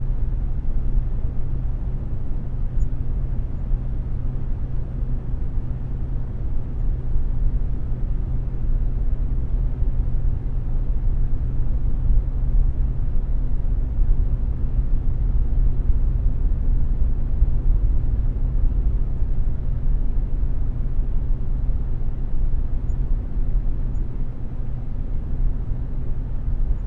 电脑风扇
描述：我的电脑冷却风扇。产生非常温暖的白噪音
标签： 计算机 冷却 风扇 噪声
声道立体声